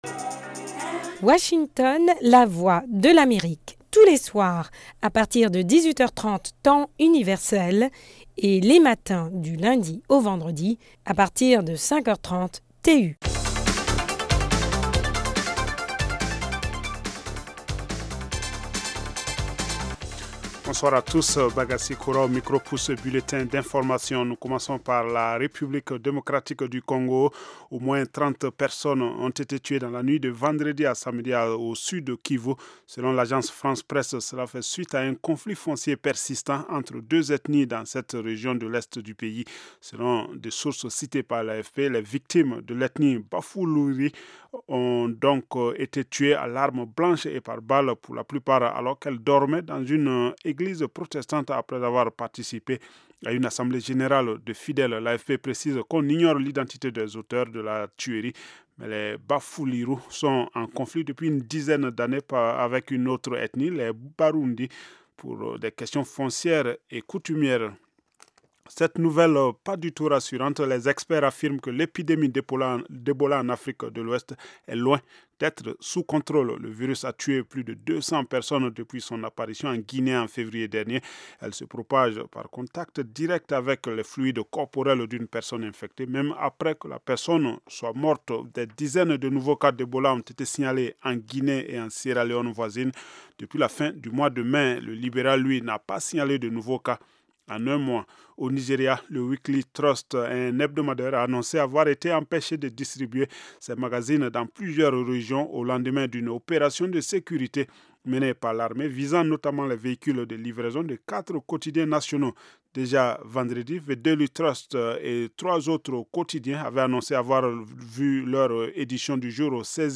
LE MAGAZINE AU FEMININ de la VOA: Un tour d’horizon des violences faites aux filles et aux femmes dans le monde, à commencer par le mariage forcé des enfants. La Ministre des Droits Humains du Burkina Faso, Julie Prudence Nigna/Somda, s’est exprimée sur ces questions lors d’une visite à la VOA à Washington.
(Le magazine débute après 5mn d’actualité du 7 juin)